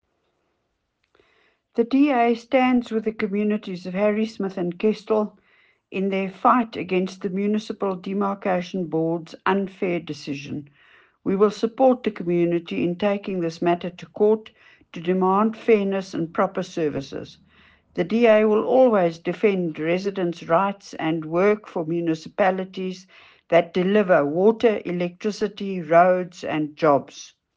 Afrikaans soundbites by Cllr Leona Kleynhans and